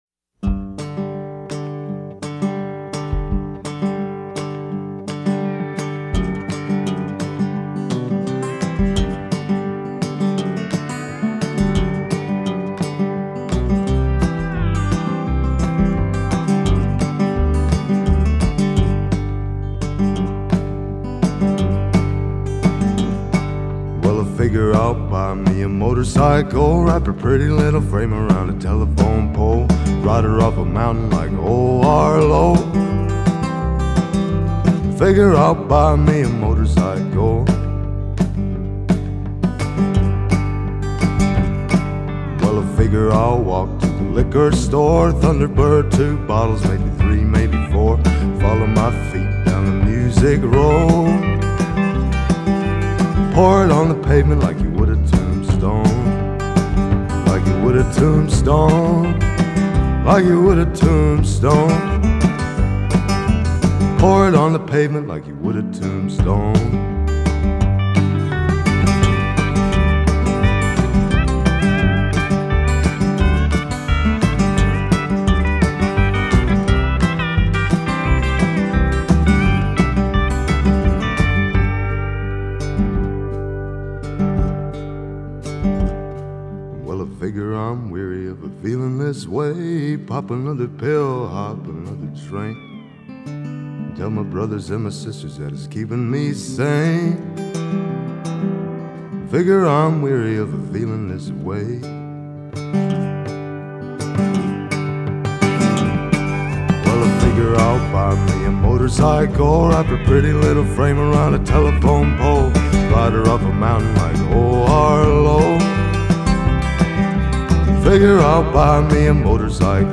Husky voiced
Canadian singer-songwriter